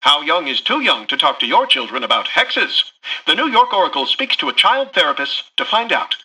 Newscaster_headline_44.mp3